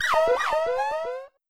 Alert5.wav